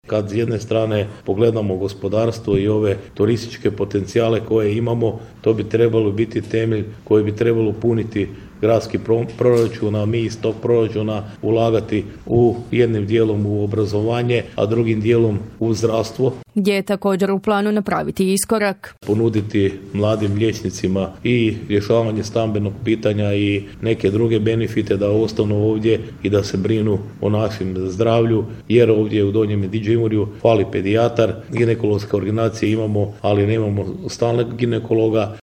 Prelog će iduće godine u listopadu biti domaćin Prvenstvu Hrvatske u karateu, najavljeno je ovog tjedna na održanoj konferenciji za medije u gradskoj vijećnici.